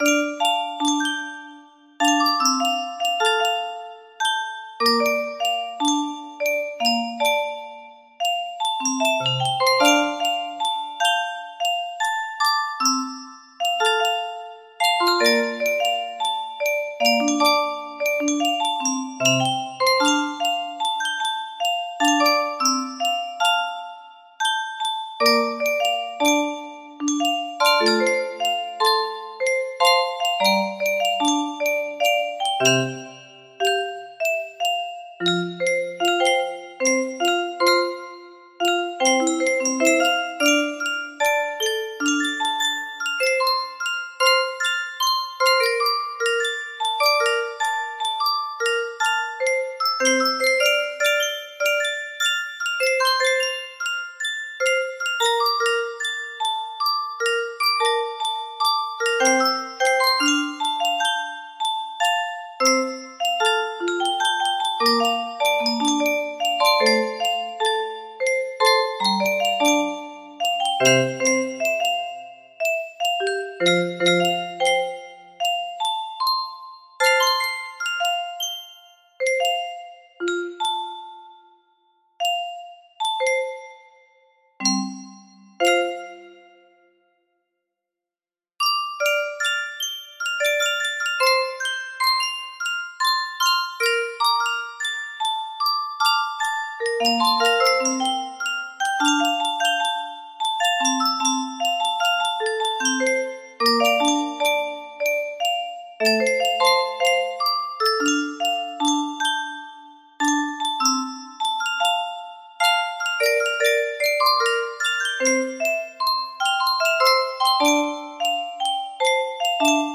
Full range 60
proper tempo no reds.